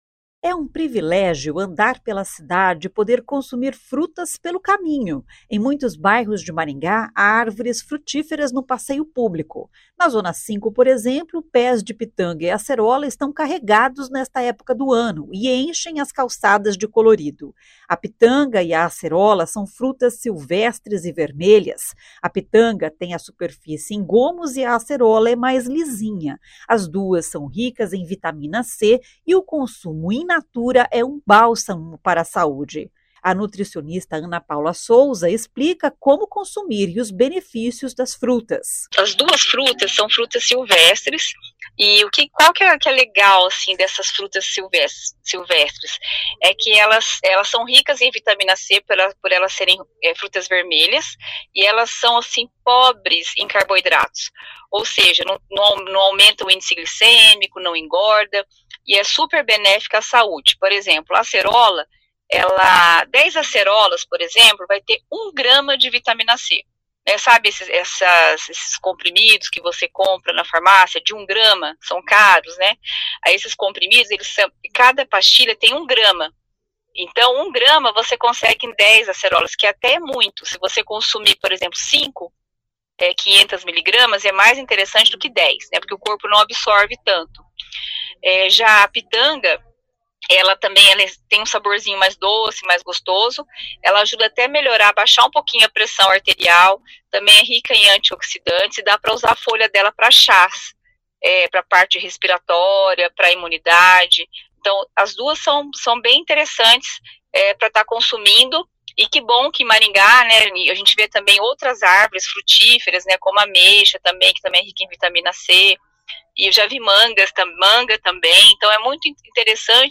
Nutricionista esclarece diferenças entre pitanga e acerola